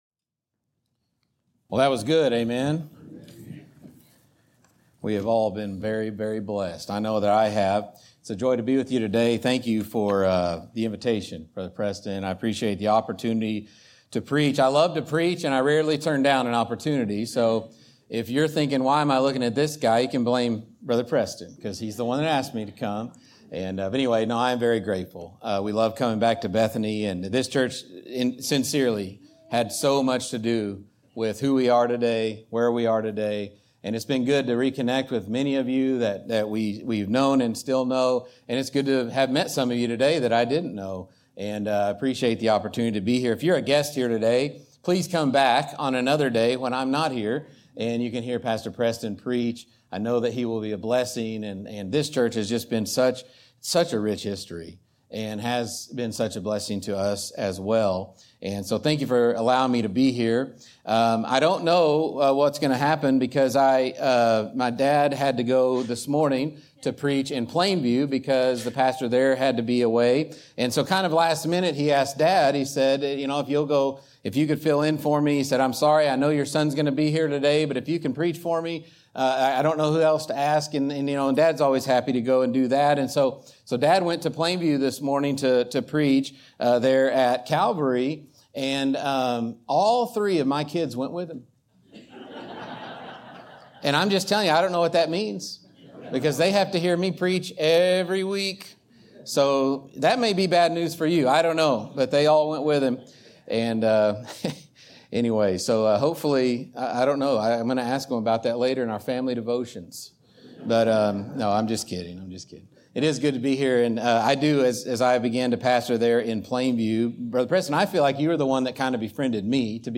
Guest & Staff Preachers at Bethany Baptist Church Scripture References: Exodus 27:1-8 , Hebrews 13:10-15